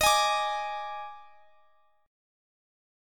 EbM7sus4 Chord
Listen to EbM7sus4 strummed